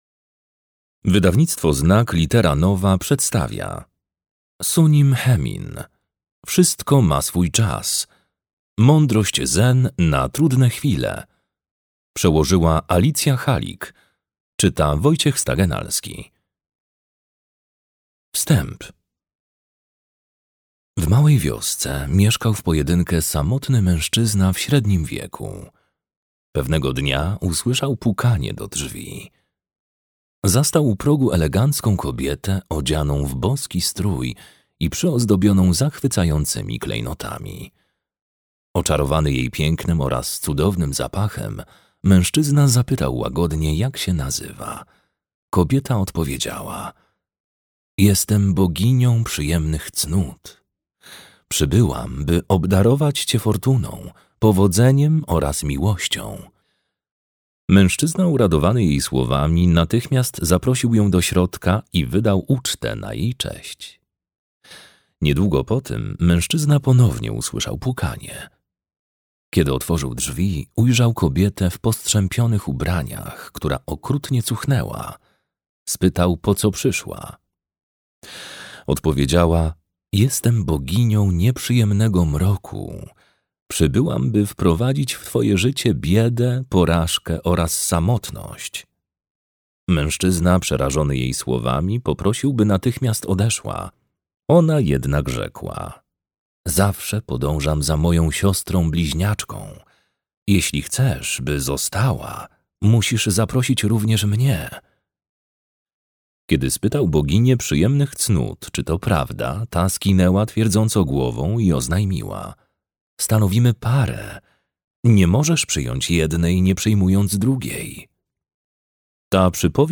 Wszystko ma swój czas. Mądrość zen na trudne chwile - Sunim Haemin - audiobook